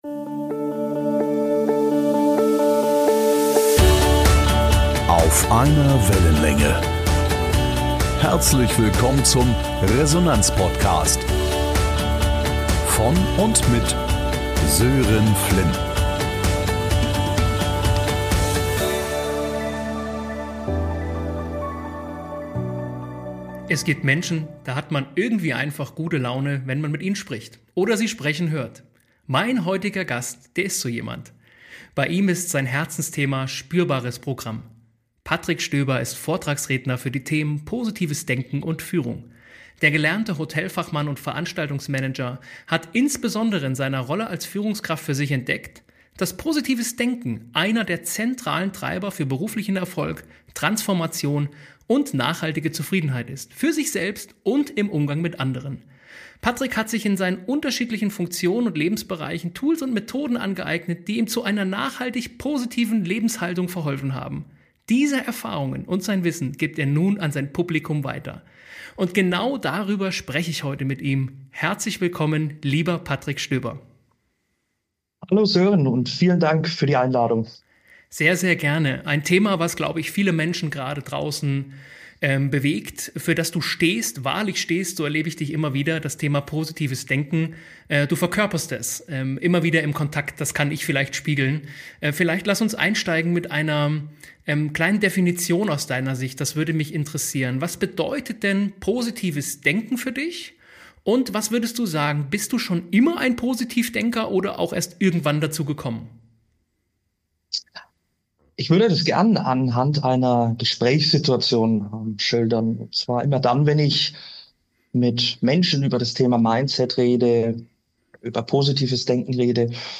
#025 Positives Denken als Resonanzfaktor | Interview